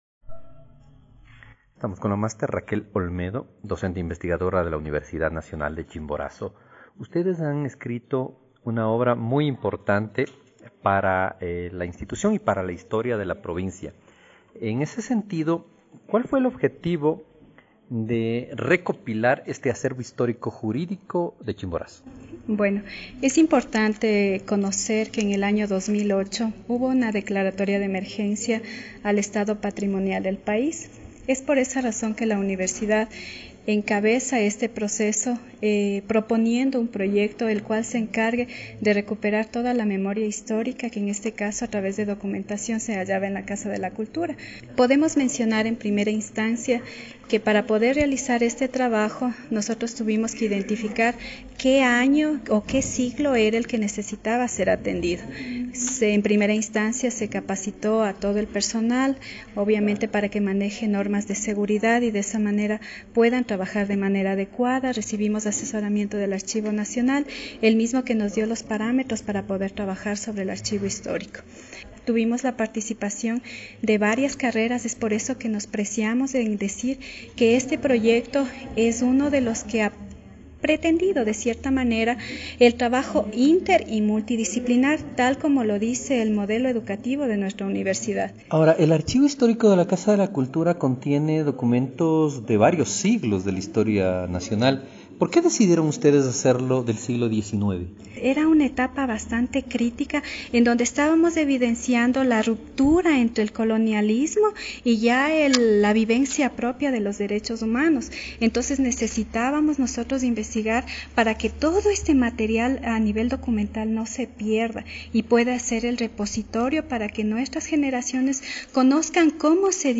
Entrevista a los autores